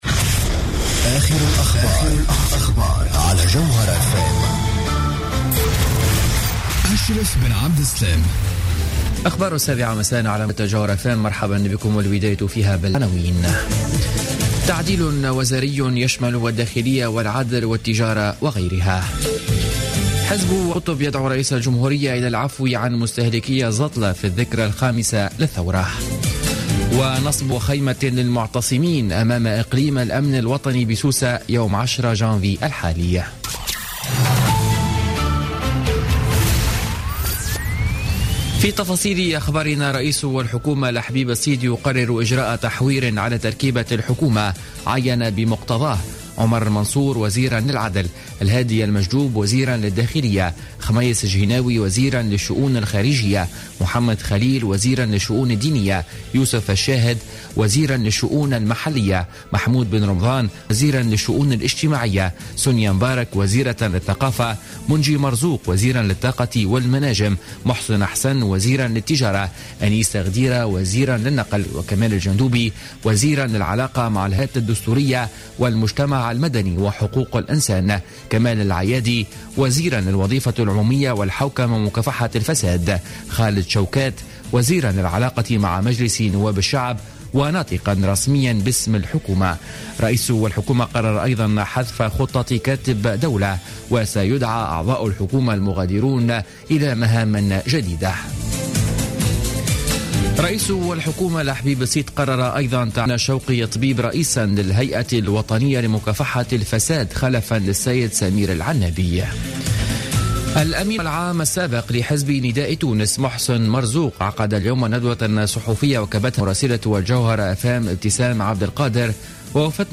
نشرة أخبار السابعة مساء ليوم الاربعاء 6 جانفي 2016